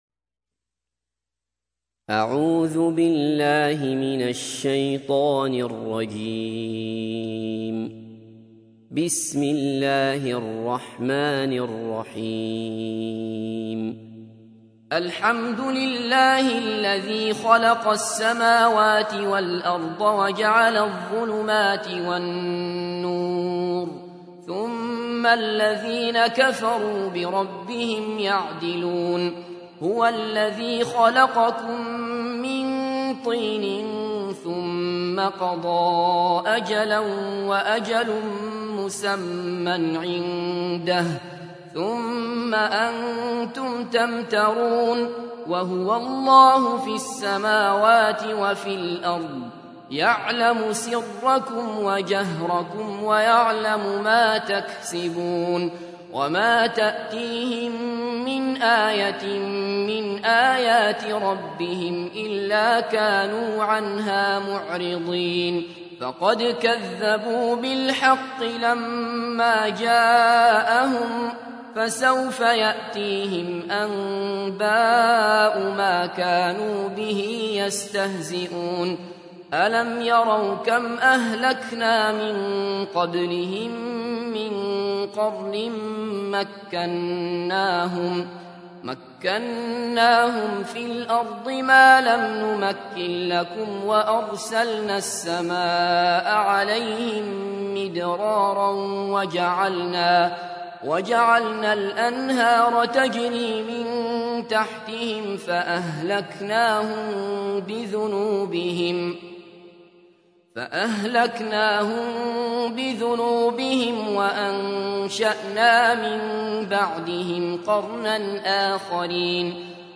تحميل : 6. سورة الأنعام / القارئ عبد الله بصفر / القرآن الكريم / موقع يا حسين